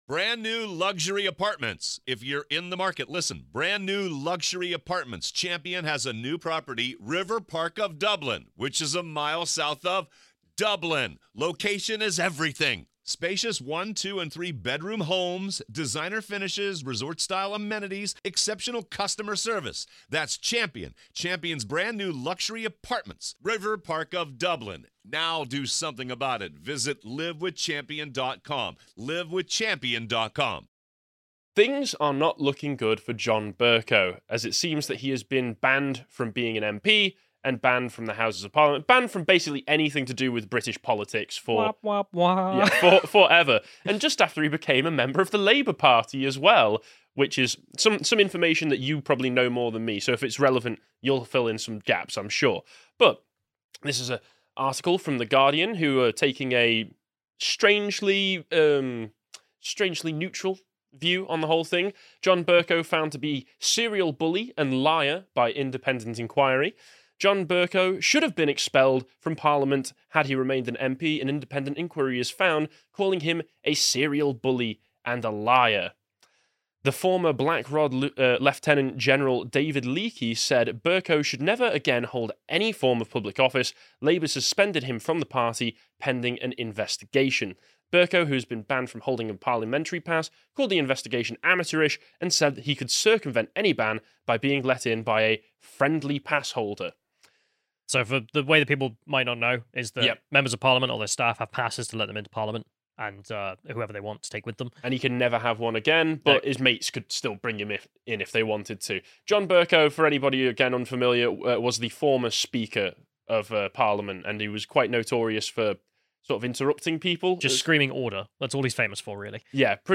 Pre-recorded Thursday 10/03/22.